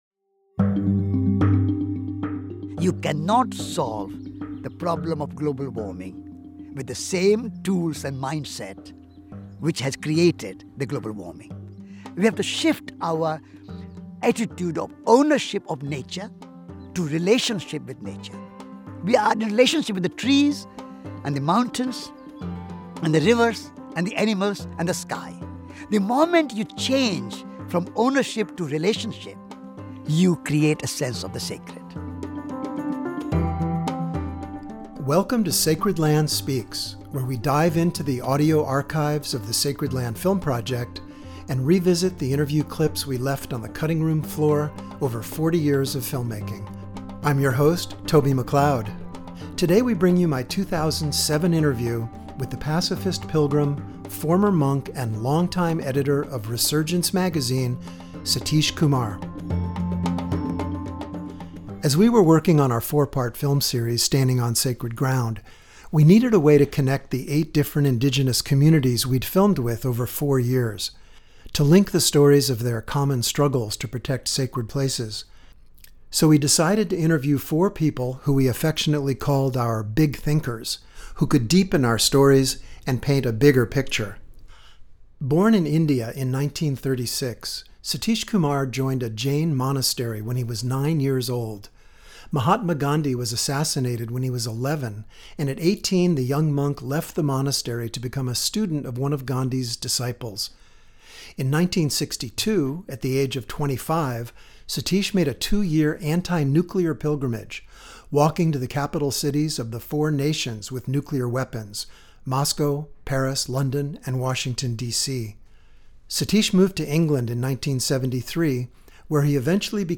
Our interview was conducted in 2007 at Green Gulch Zen Center in northern California.